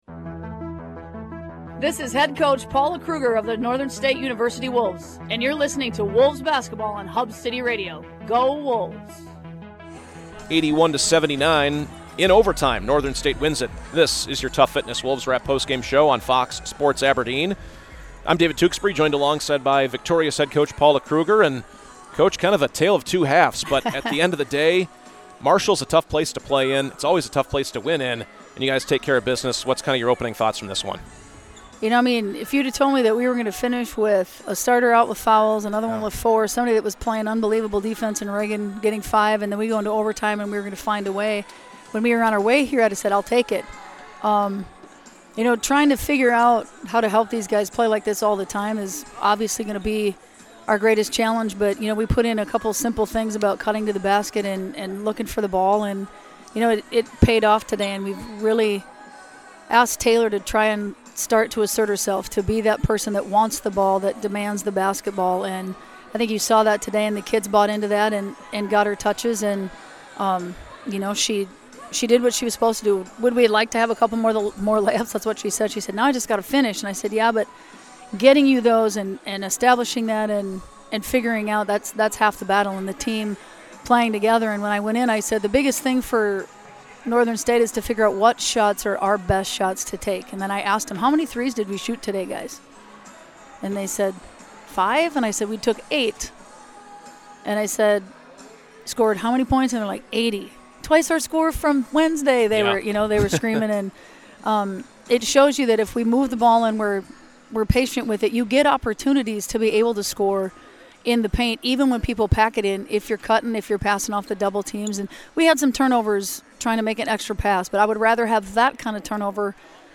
Postgame show